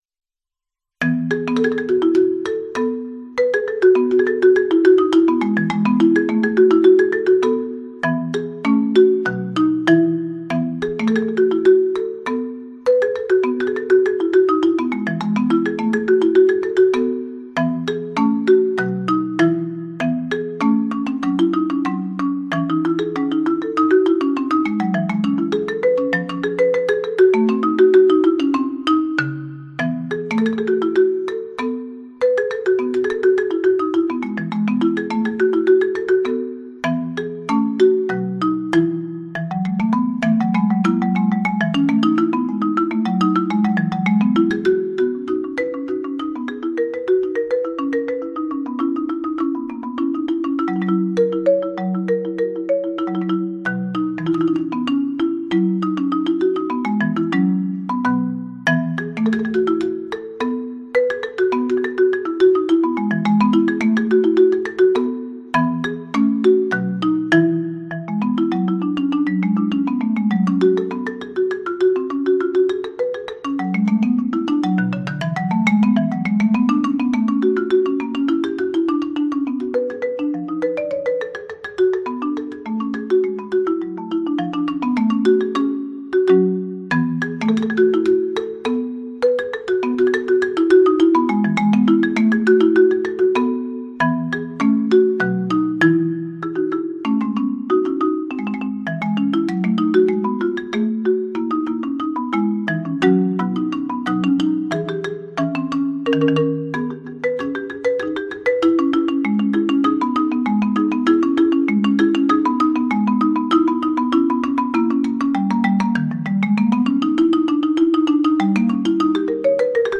Bach auf Vibraphon und Marimba
Stücke von Johann Sebastian Bach, gespielt auf Vibraphon und Marimba.